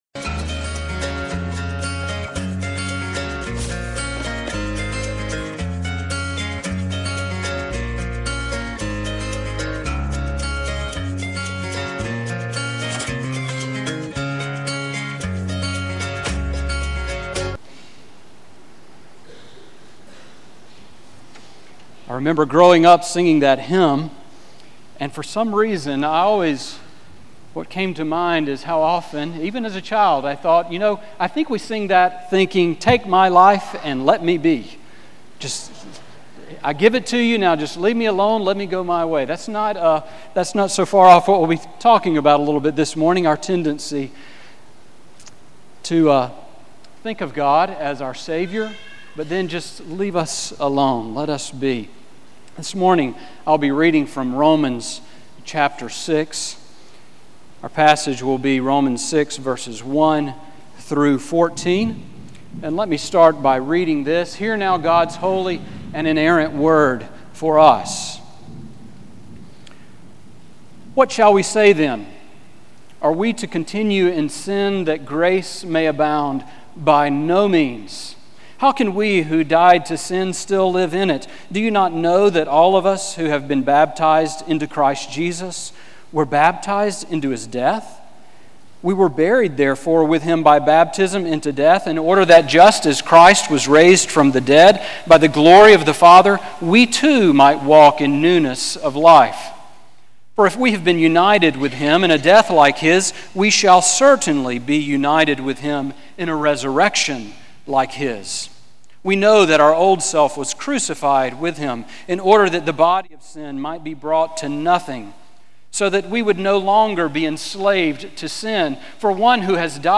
Sermon on romans 6:1-14 from April 27